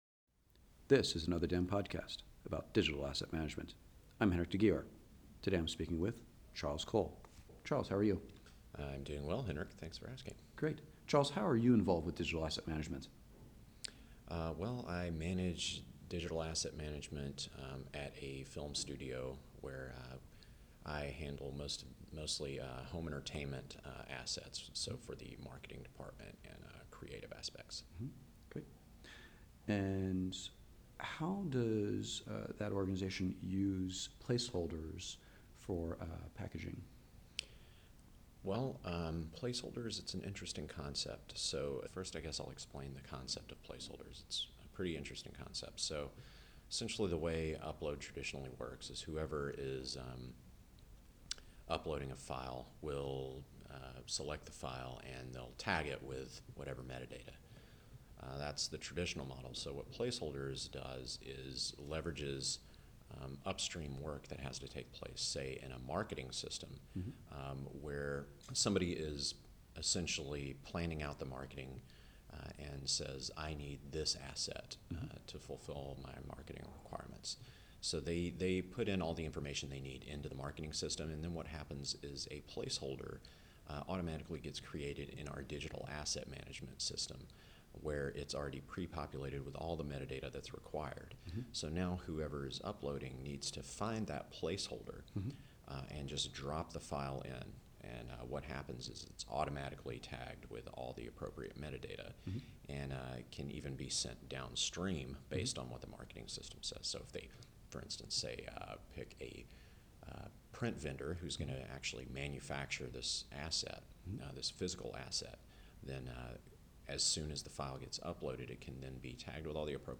Here are the questions asked: